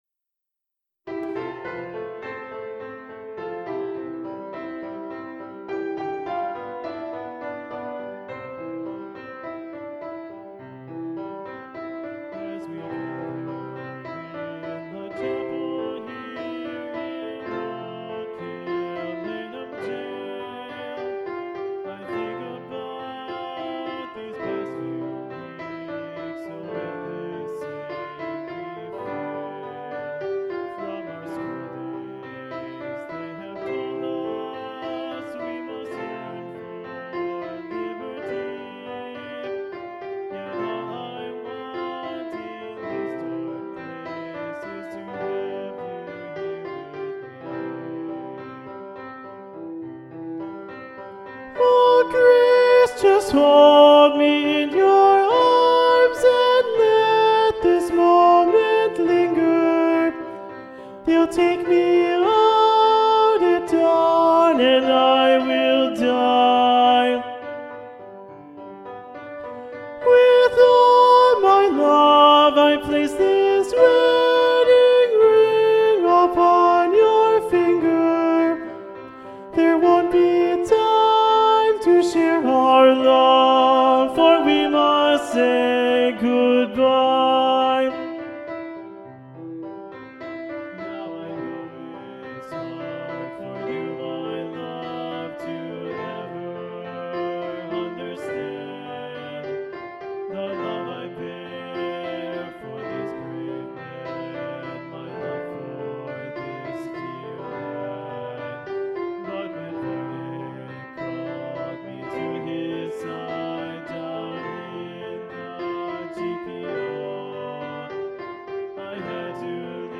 Alto 2